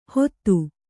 ♪ hottu